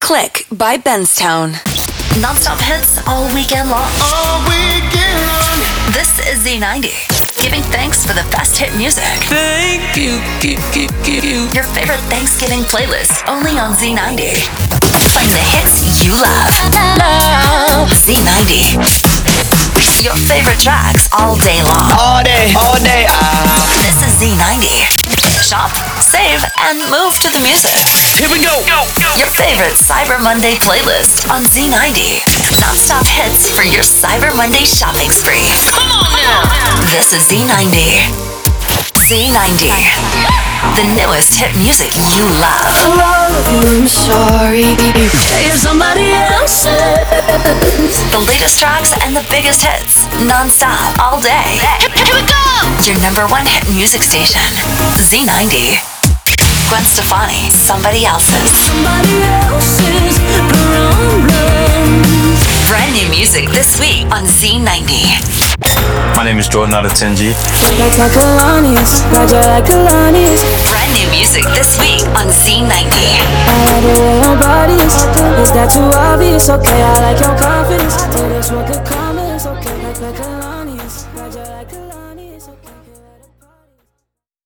Containing shelled produced promos and sweepers, ramploops, branded song intros, artist IDs, listener drops, song hooks, musicbeds, individual imaging workparts, and more.